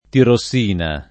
tirossina [ tiro SS& na ]